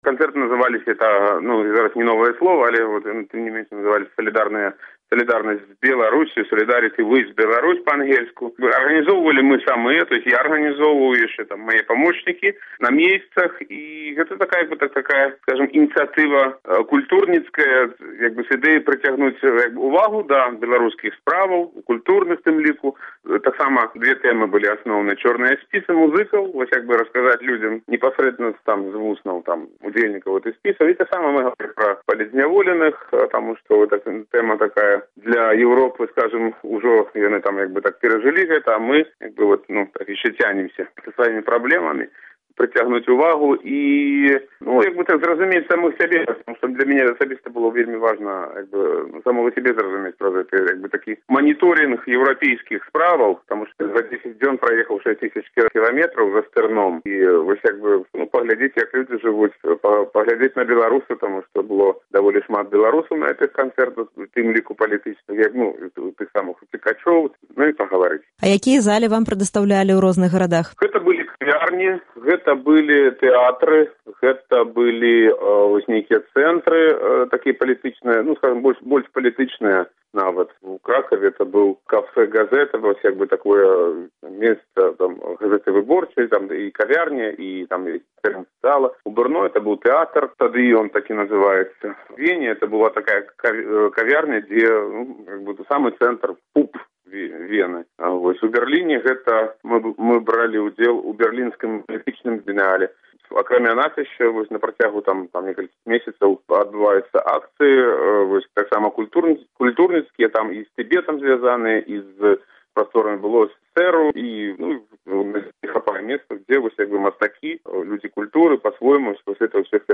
Інтэрвію са Зьміцерам Вайцюшкевічам